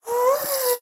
minecraft / sounds / mob / ghast / moan4.ogg
moan4.ogg